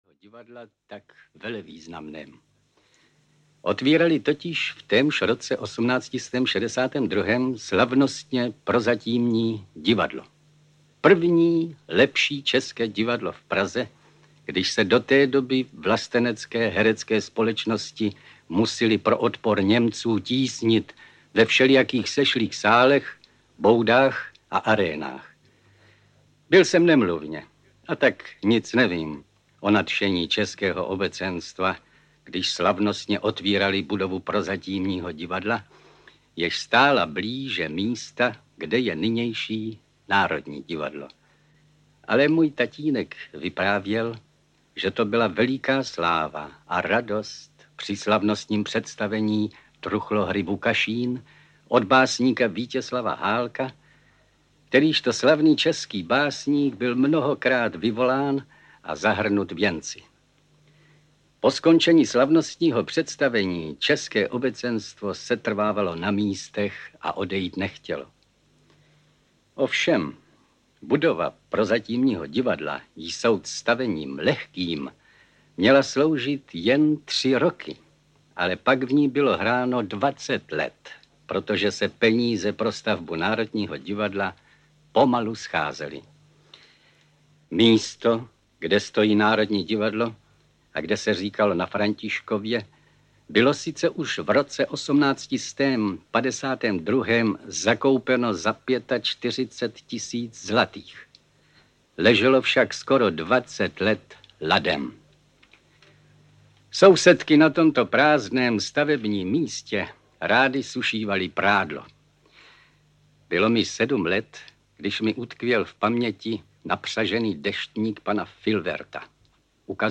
Audio kniha
Ukázka z knihy
• InterpretFrantišek Smolík